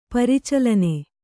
♪ paricalane